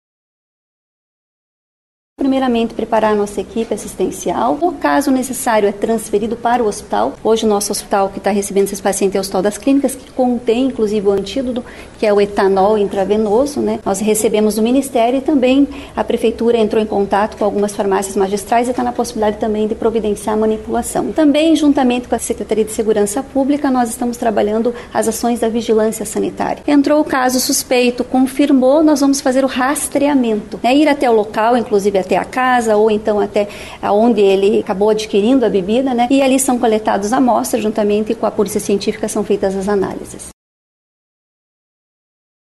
Em entrevista, a secretária da saúde de Curitiba, Tatiane Filipak, falou sobre as ações de monitoramento já adotadas, com destaque para a identificação dos casos, seguida de fiscalizações para apontar a origem da intoxicação.